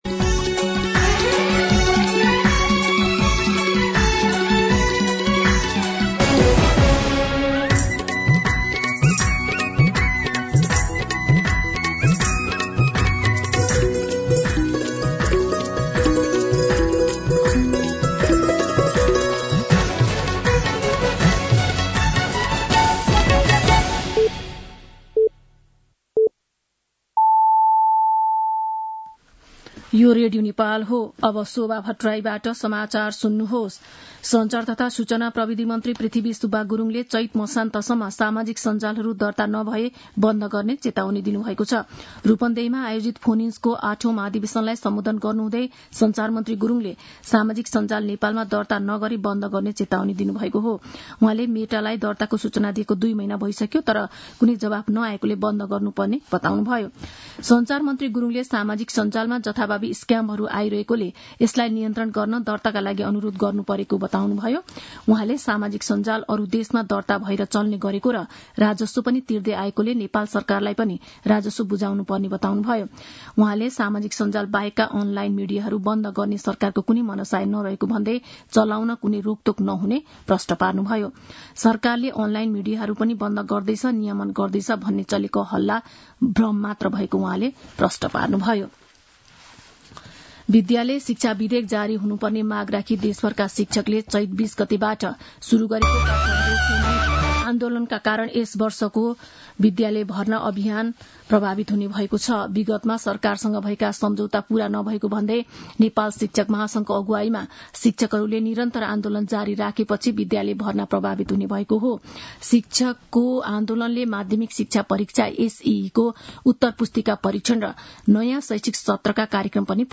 मध्यान्ह १२ बजेको नेपाली समाचार : ३० चैत , २०८१
12-pm-Nepali-News-3.mp3